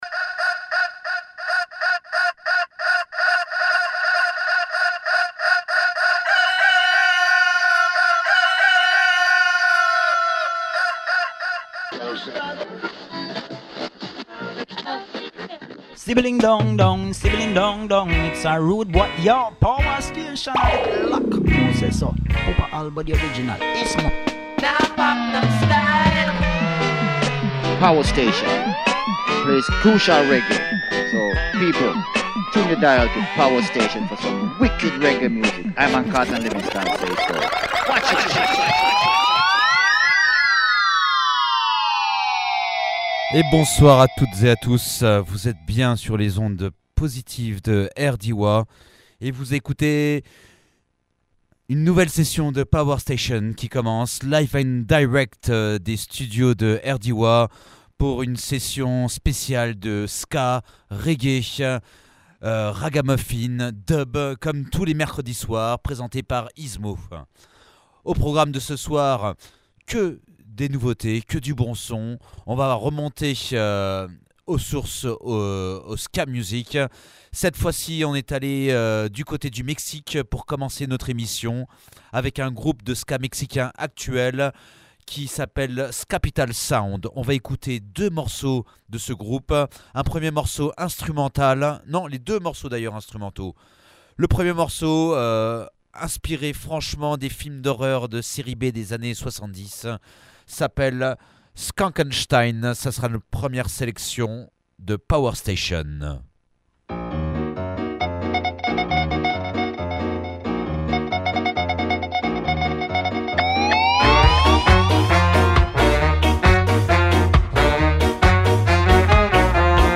dub , reggae , ska